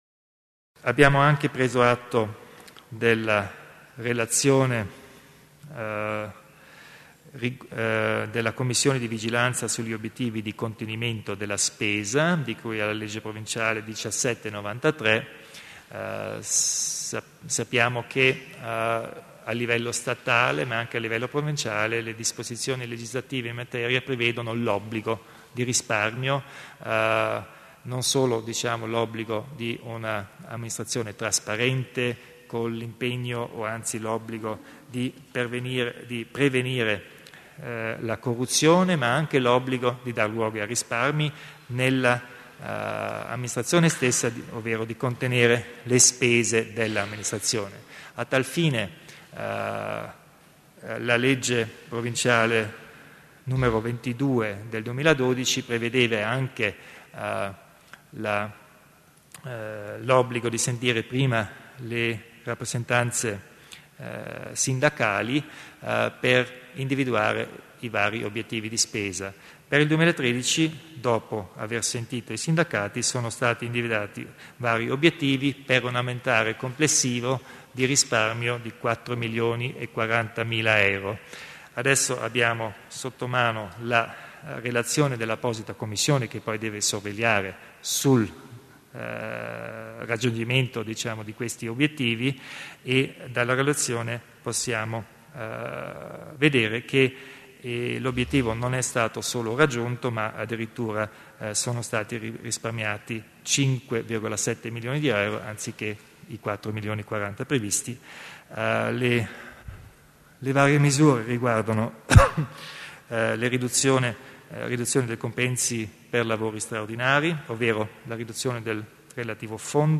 Il Presidente Kompatscher illustra gli interventi in tema di riduzione della spesa